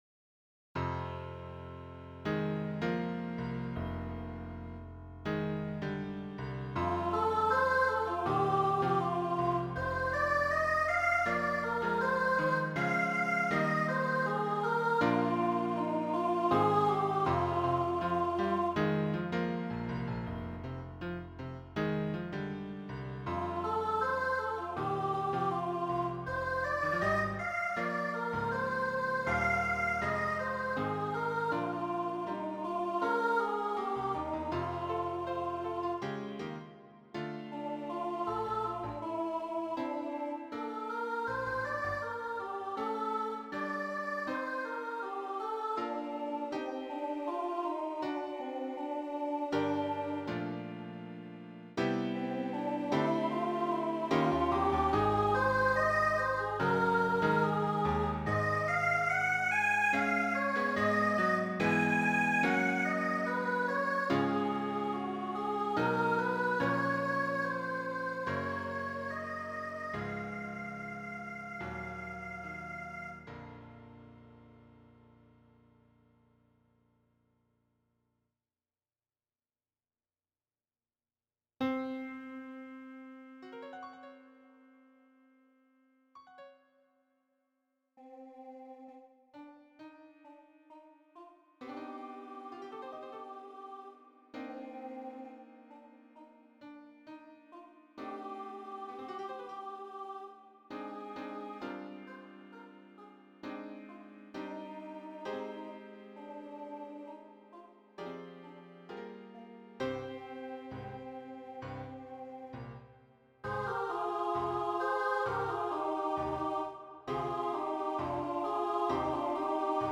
children's voices